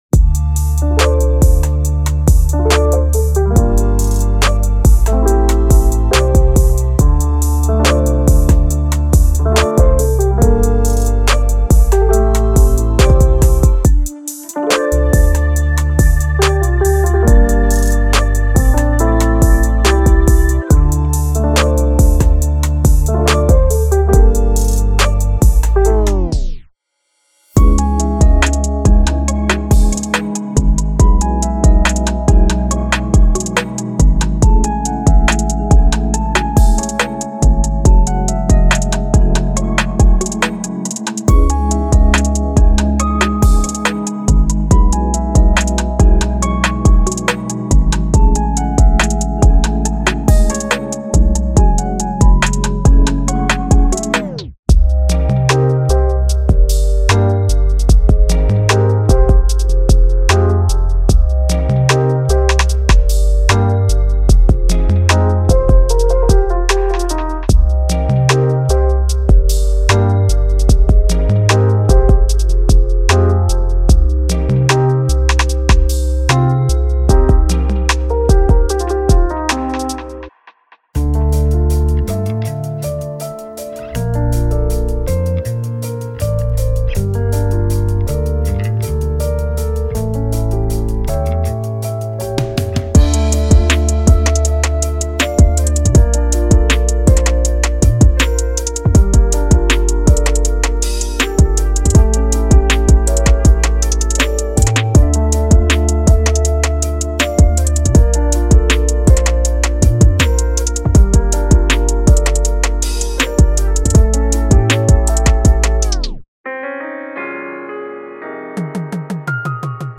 その名の通り、このパックの主な焦点は、美しく感情豊かなメロディとハーモニーにあります。
デモサウンドはコチラ↓
Genre:Chill Trap
Tempo/Bpm 70-90